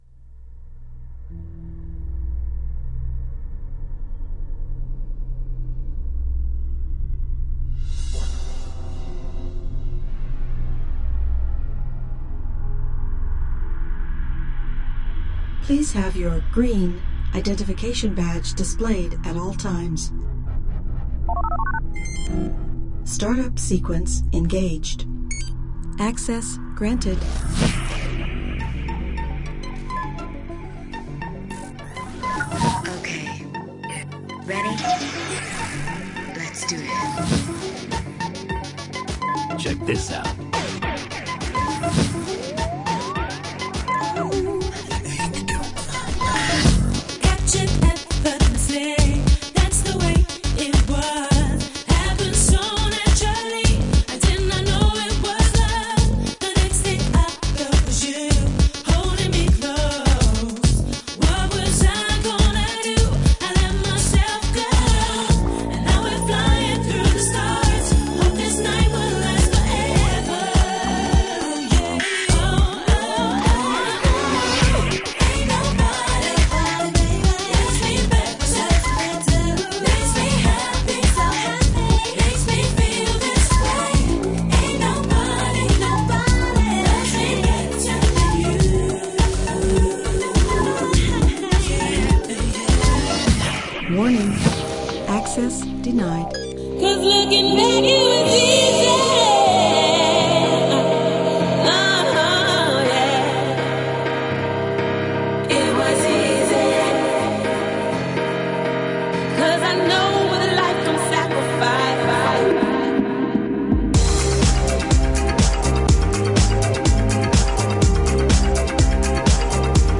Ambient Intro